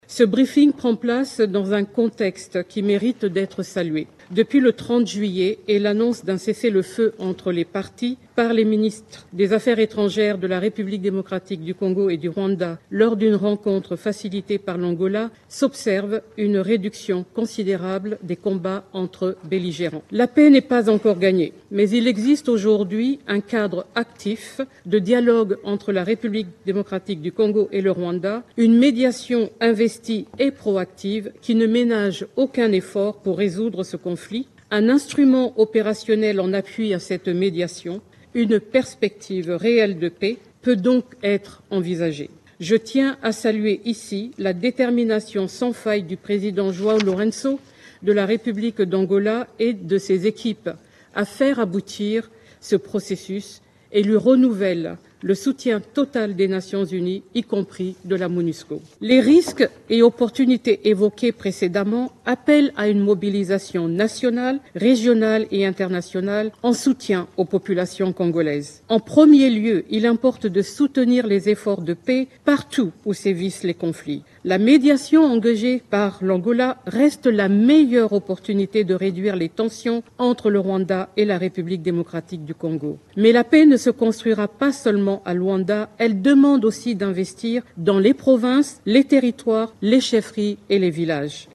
La représentante spéciale du Secrétaire général des Nations unies en RDC le dit dans le nouveau rapport du Secrétaire général de l’ONU sur la situation en République Démocratique du Congo qu’elle a présenté ce lundi 30 septembre devant le Conseil de sécurité des Nations Unies à New York.
Vous pouvez écouter la cheffe de la MONUSCO dans cet extrait :